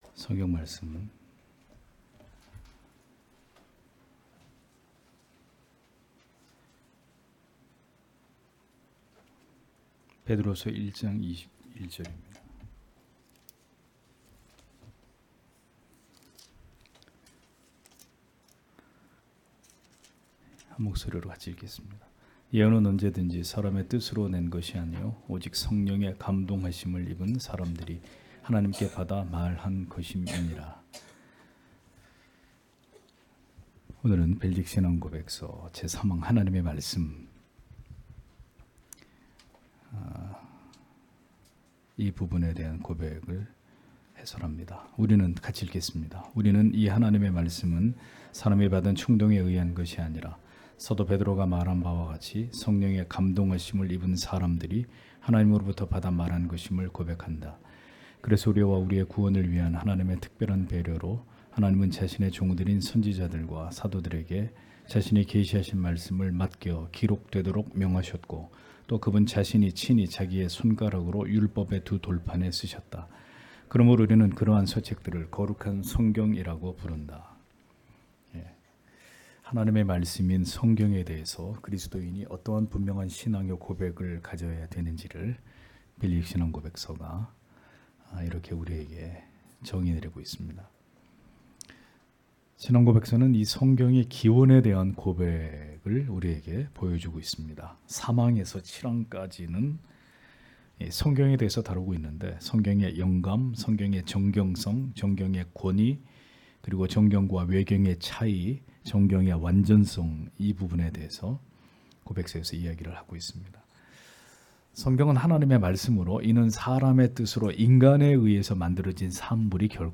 주일오후예배 - [벨직 신앙고백서 해설 3] 제3항 하나님의 말씀 (벧후 1:21)